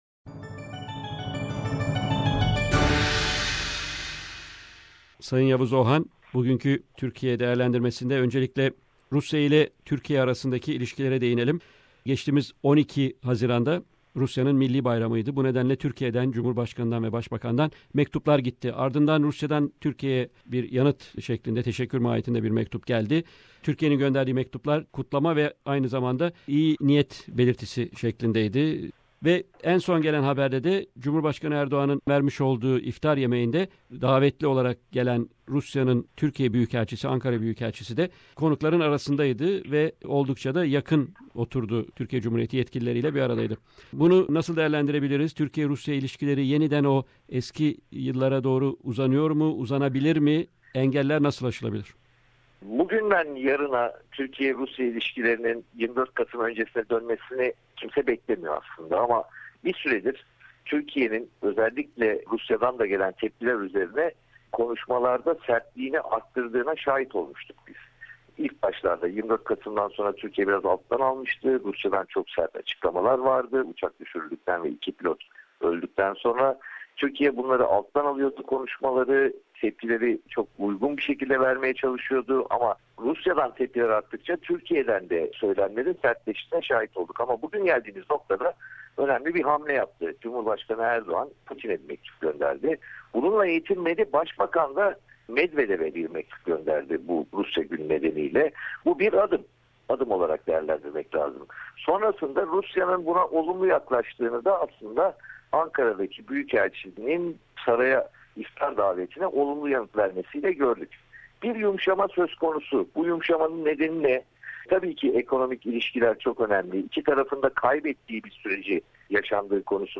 Weekly Stringer Report from Istanbul